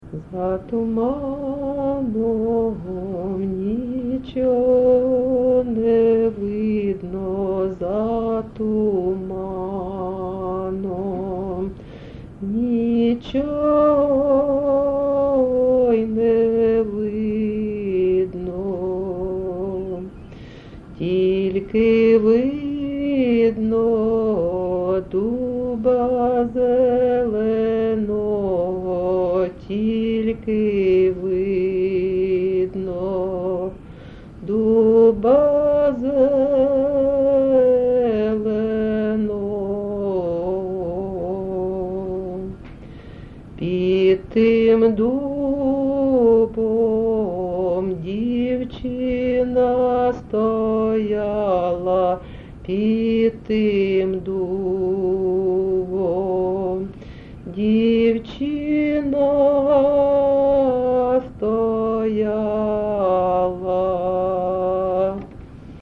ЖанрПісні з особистого та родинного життя
Місце записус-ще Олексієво-Дружківка, Краматорський район, Донецька обл., Україна, Слобожанщина